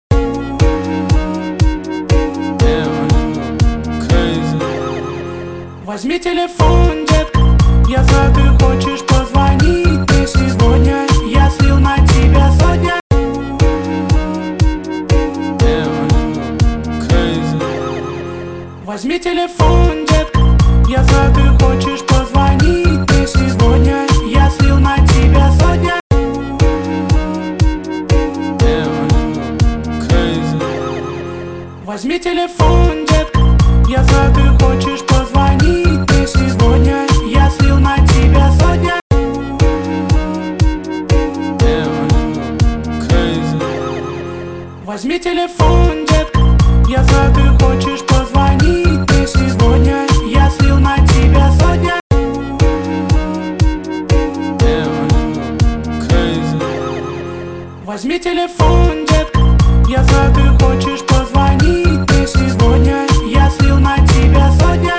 stereo Рэп